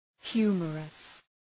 {‘hju:mərəs}